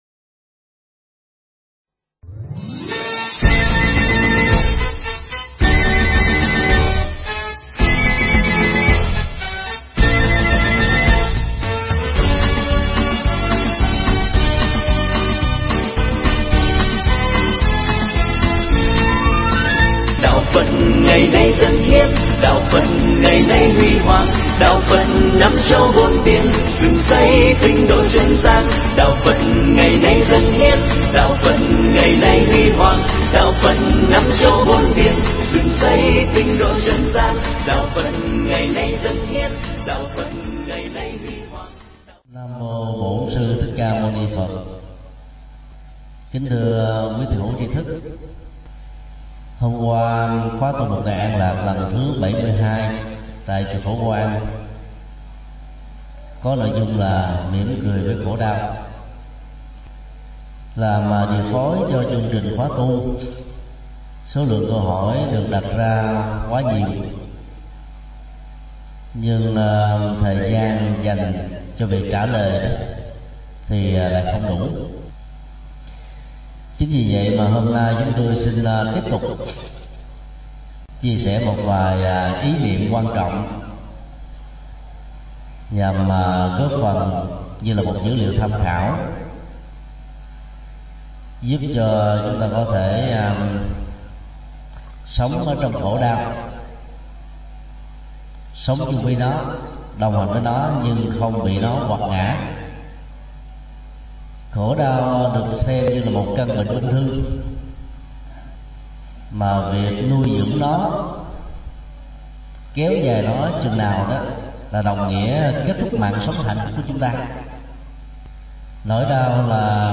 Tải mp3 Pháp thoại Cười Với Khổ Đau được thầy Thích Nhật Từ giảng tại Chùa Giác Ngộ, ngày 06 tháng 07 năm 2009.